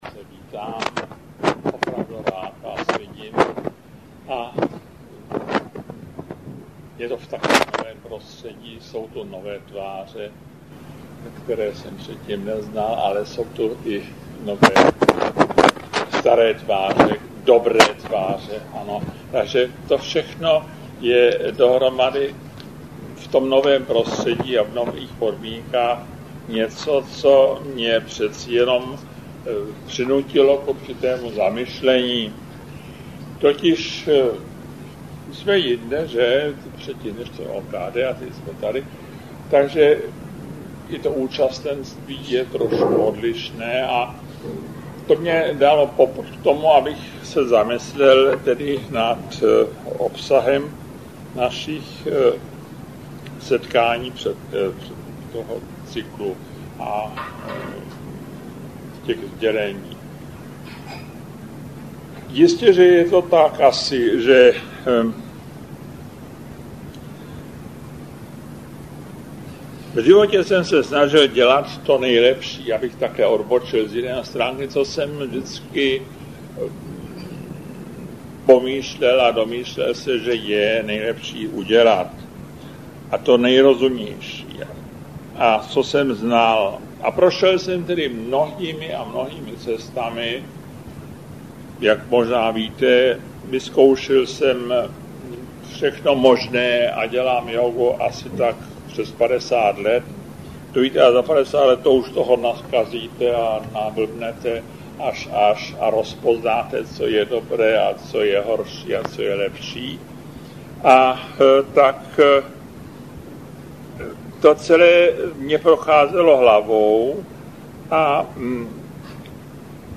Nahráno 9. 1. 2002, Galerie Cesty ke Světlu, Praha, délka 107 min.